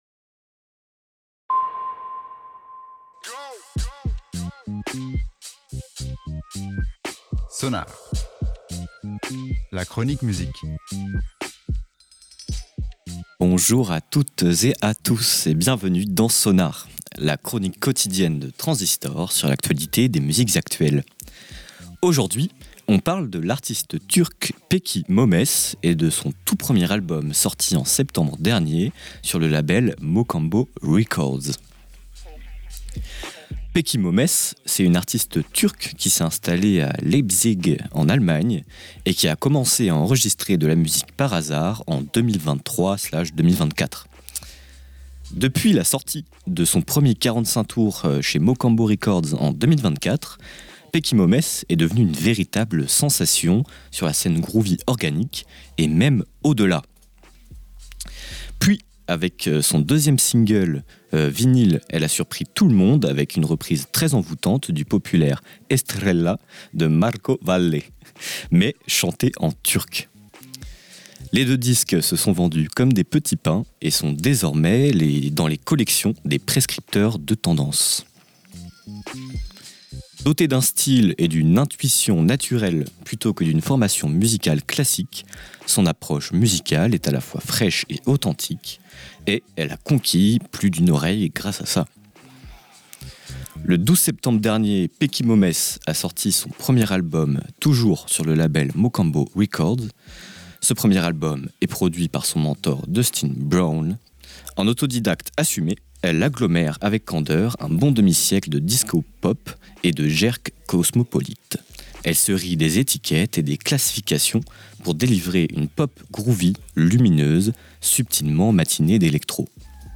groove organique